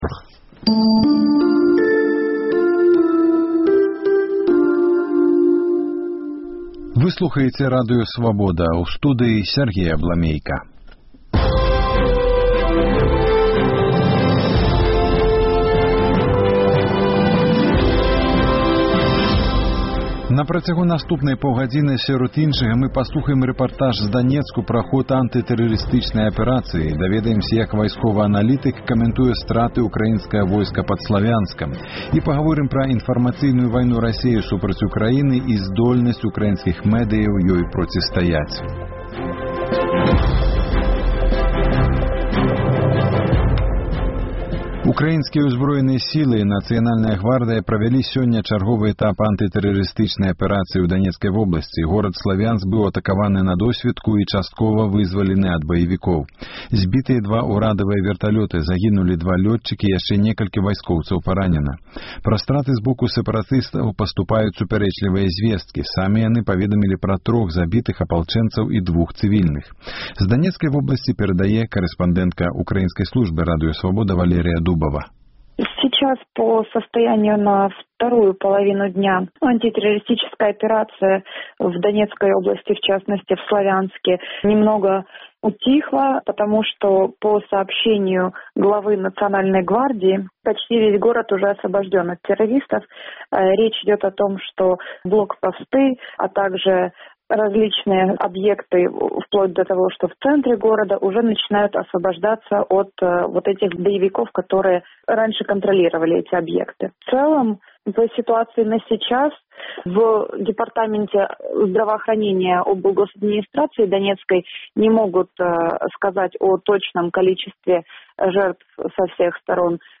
рэпартаж з Данецку